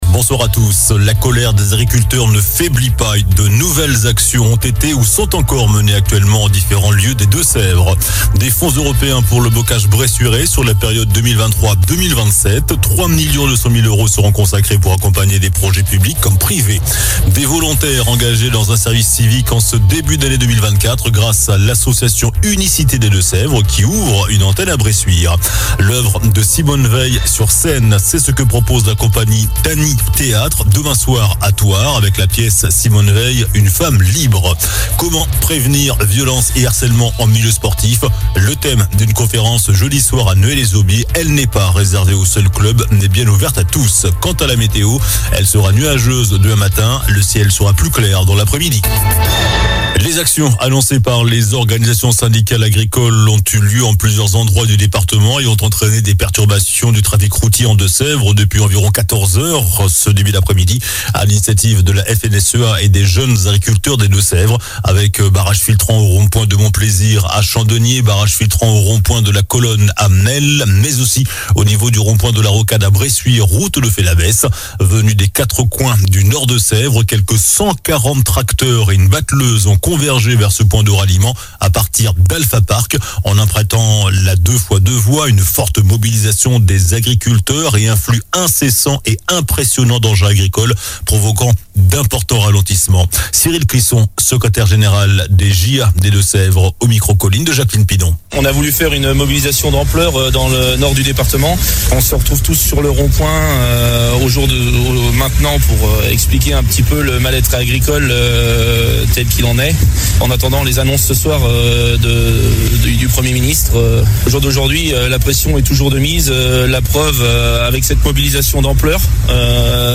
Journal du mardi 31 janvier (soir)